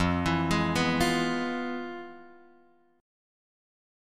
F Minor Major 7th Flat 5th